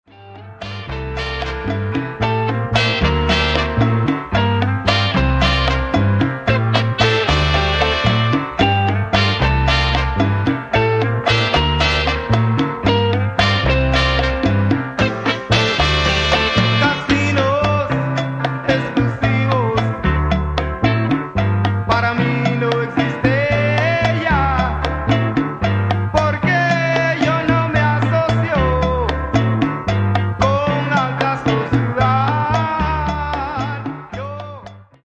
Genre:   Latin Disco Soul